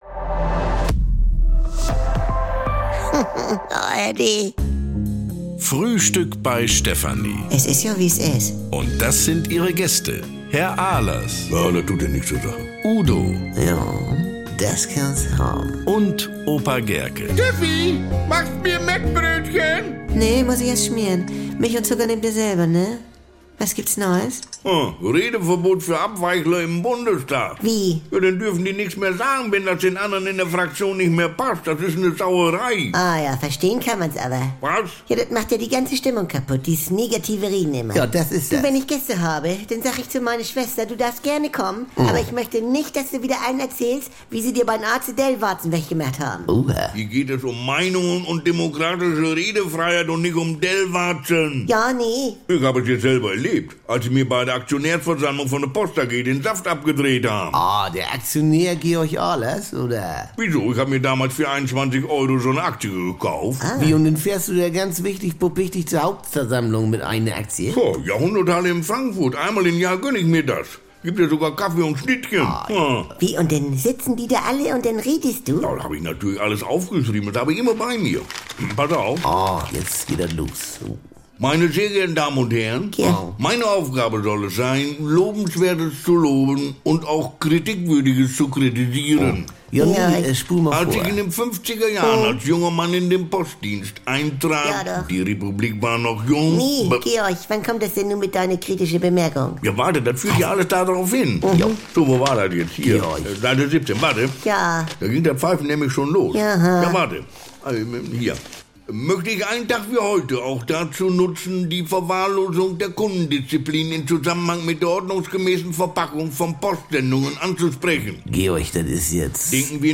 Garantiert norddeutsch mit trockenen Kommentaren, deftigem Humor und leckeren Missverständnissen.